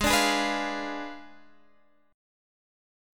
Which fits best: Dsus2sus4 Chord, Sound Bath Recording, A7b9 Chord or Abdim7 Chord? Abdim7 Chord